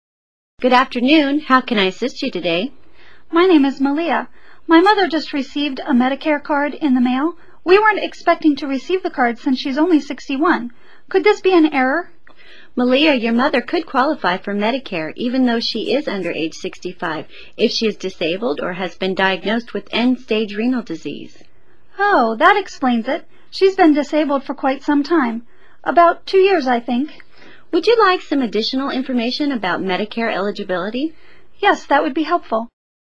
Select the Audio icon to hear part of their phone conversation or select the Text icon to read the transcript of their call.